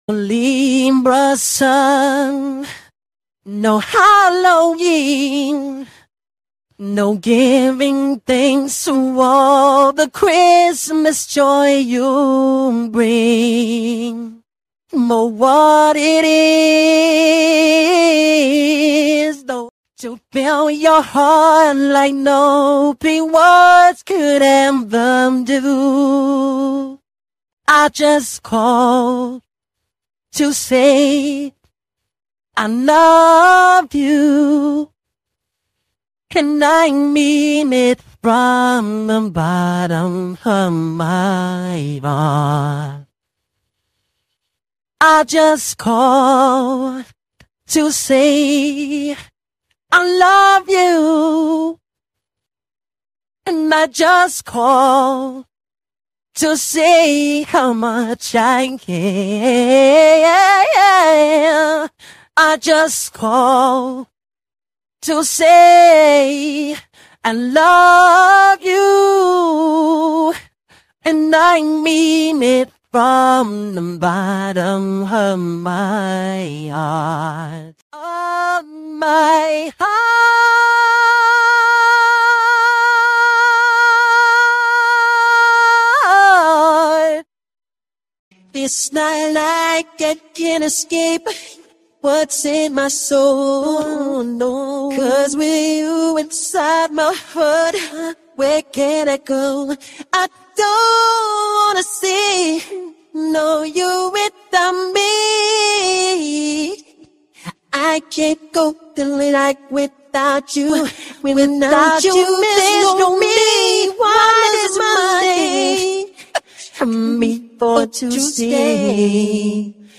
No, they are not "computer made" & other non-sense, I know he recorded these in the present time, even re-rec 'Why' differently, and it's his own raw voice, no studio acapella (like in Part 1)!